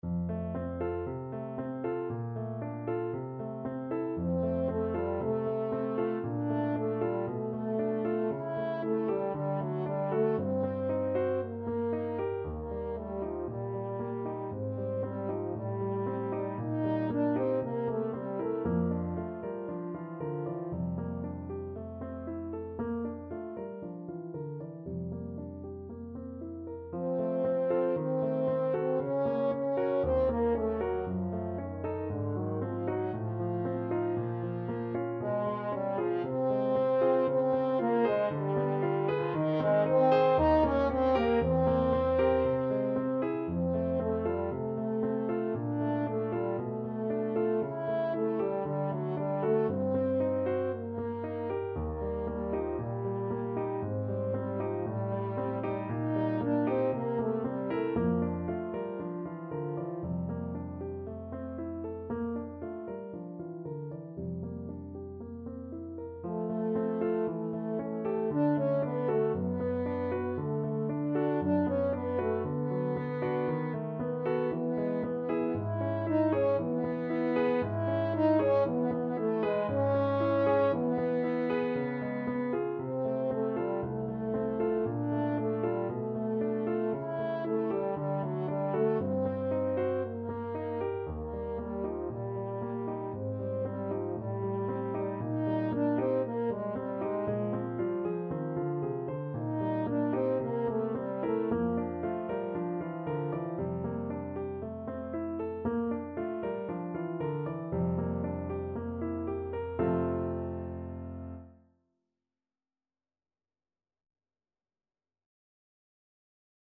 French Horn version
4/4 (View more 4/4 Music)
Allegro moderato =116 (View more music marked Allegro)
Classical (View more Classical French Horn Music)